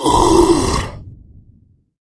troll_warrior_damage.wav